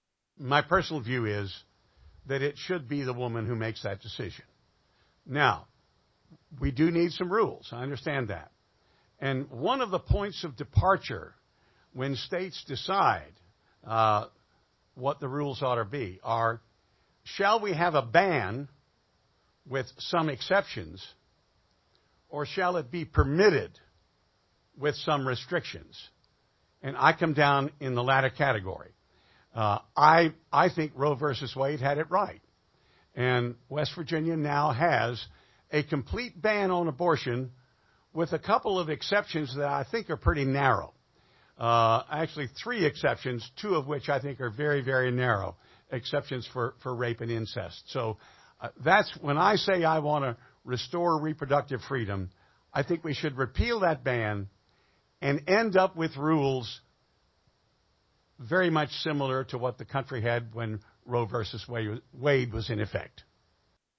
Jefferson Co. Senate Candidates Debate Education, Environment, Abortion - West Virginia Public Broadcasting